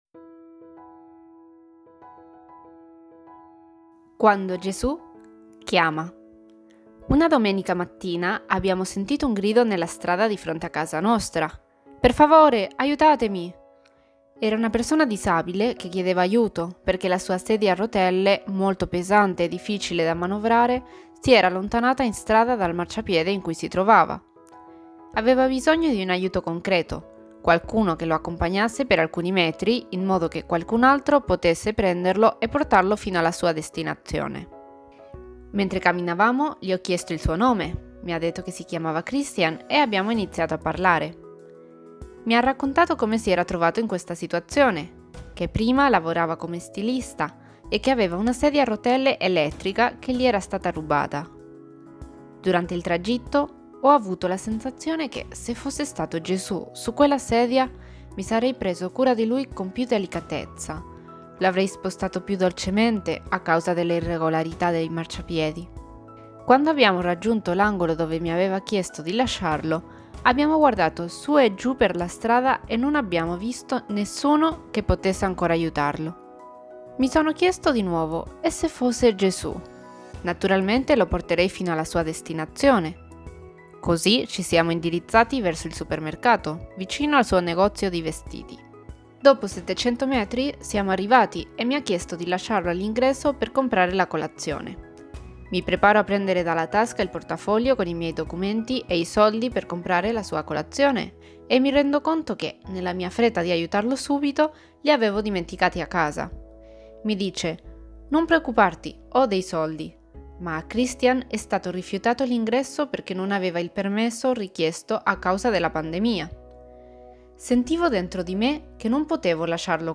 Storie > Audioletture